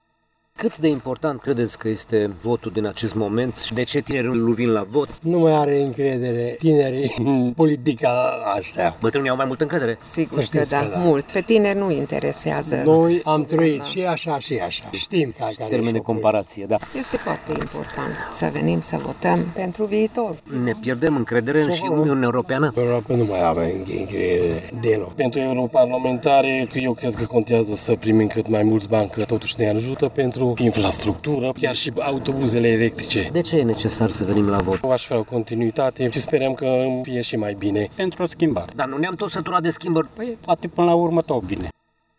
Primele opinii ale cetățenilor din Târgu Mureș, la ieșirea de la urne, sunt dintre cele mai diverse.